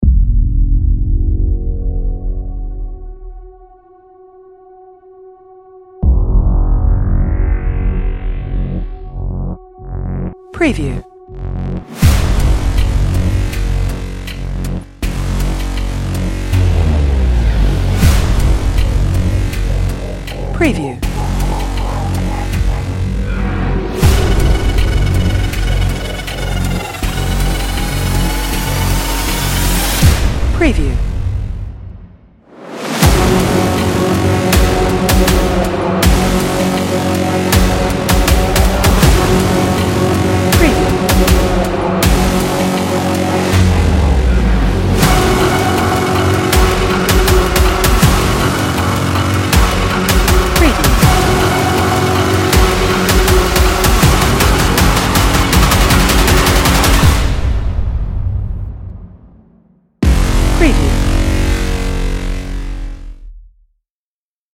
Ticking-Clock Music
Suspenseful Ticking Clock Music for Maximum Impact